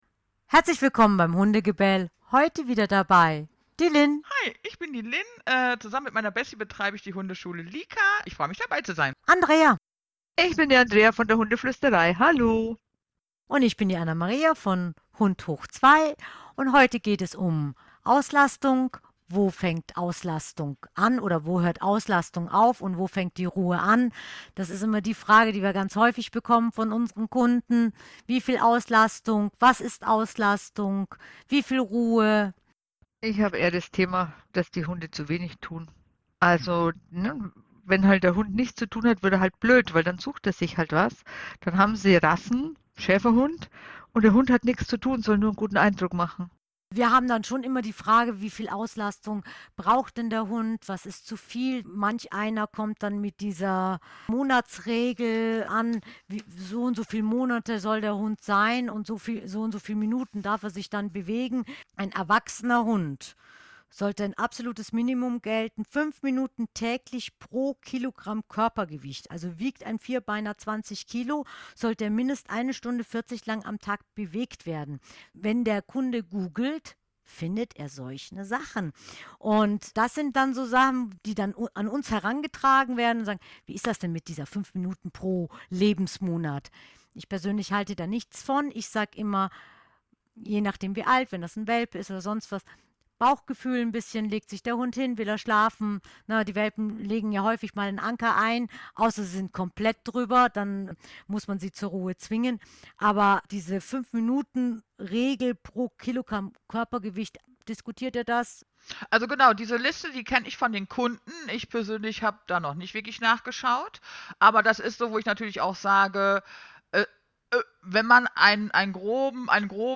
Vermerk: Wir sprechen auch CBD-Öl an - im Podcast hört sich das wie COPD an es ist CBD gemeint ;-) Mehr